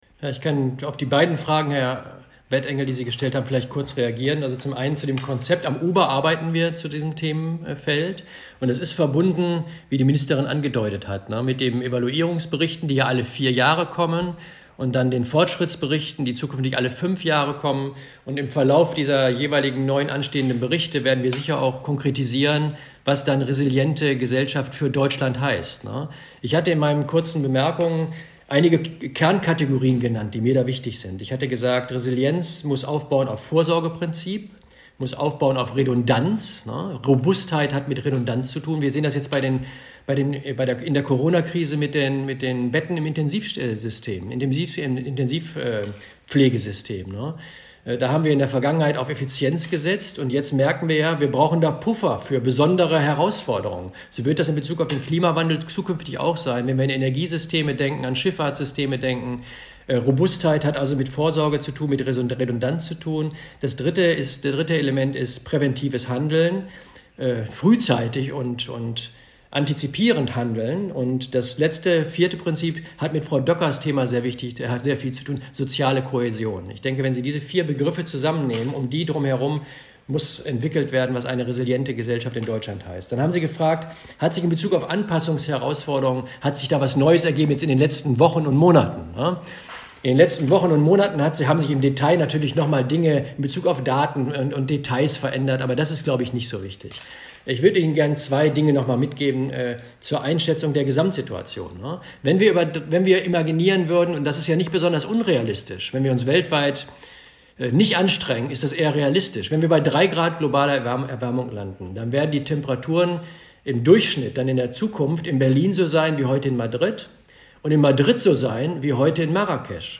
O-Töne von Dirk Messner
Antwort UBA-Präsident Dirk Messner: Frage 1